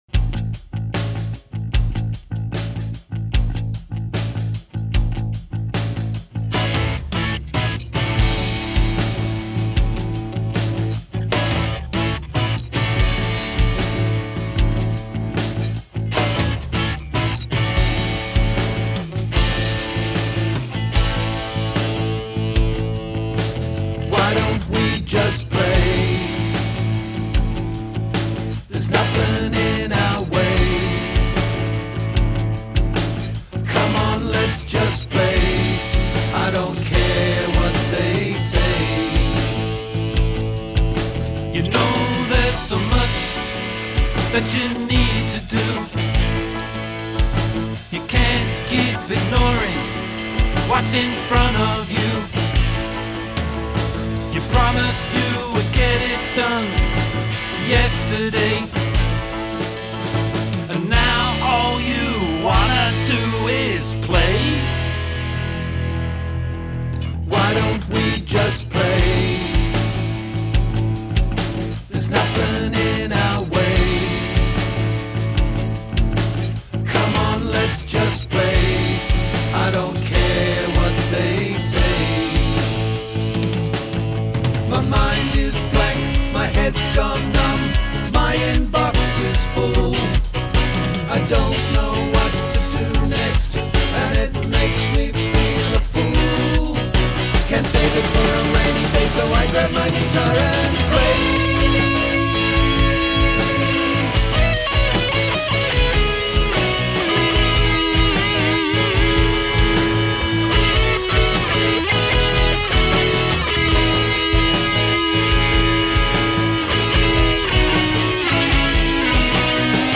Drums.
Vocals, Guitars & Keyboards
Vocals & Bass